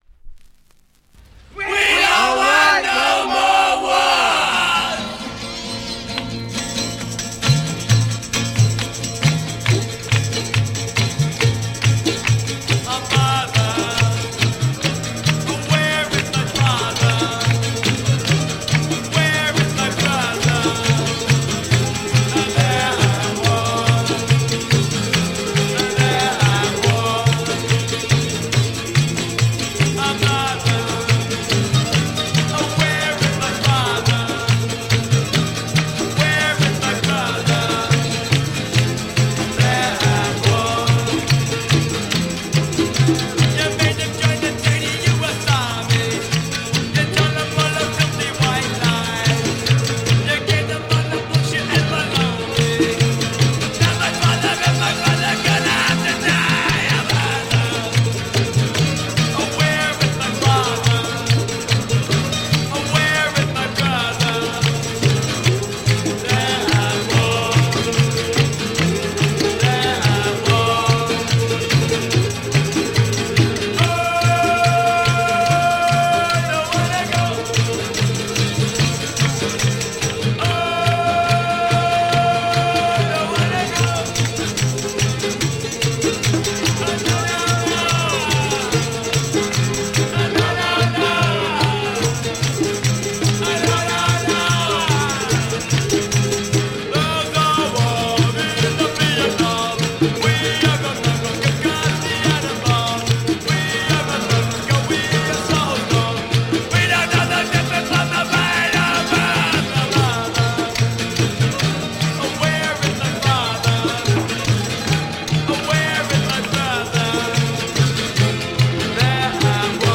Hippy blues soul psych rock